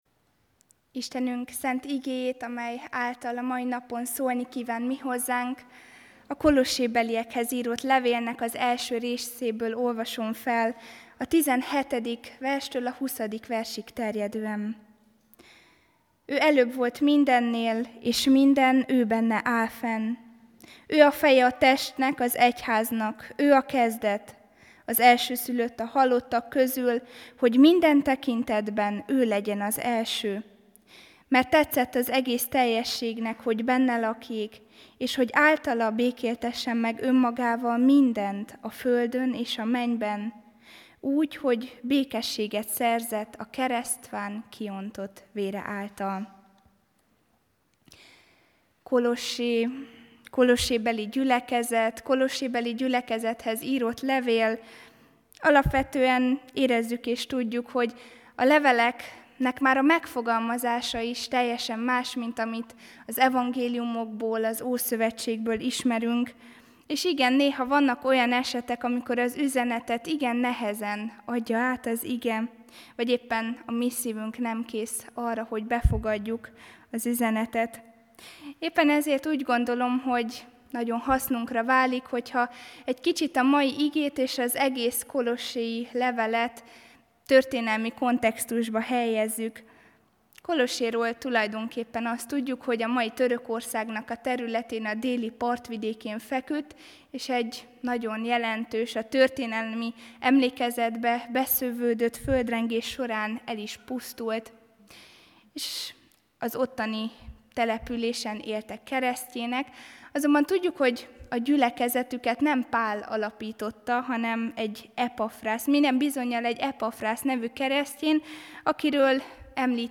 AZ IGEHIRDETÉS LETÖLTÉSE PDF FÁJLKÉNT AZ IGEHIRDETÉS MEGHALLGATÁSA